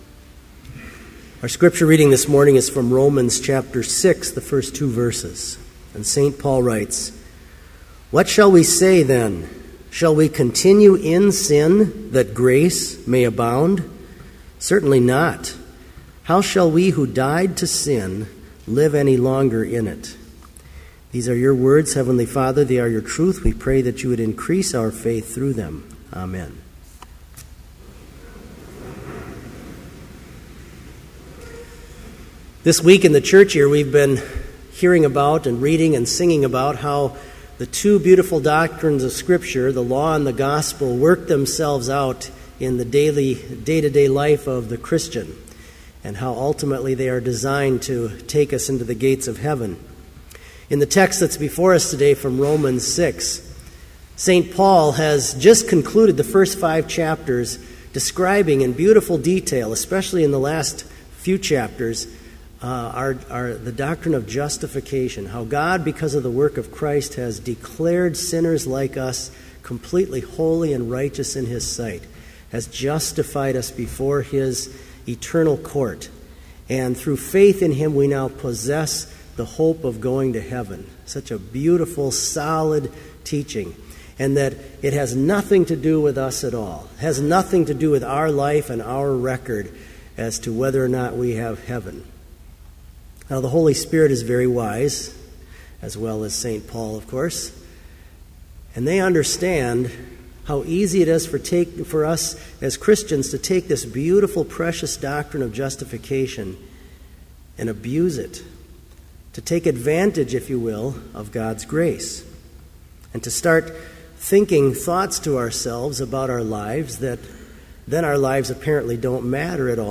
Complete Service
• Homily
• Prayer for Friday Morning, pp. 170-171 (in unison)
This Chapel Service was held in Trinity Chapel at Bethany Lutheran College on Friday, October 4, 2013, at 10 a.m. Page and hymn numbers are from the Evangelical Lutheran Hymnary.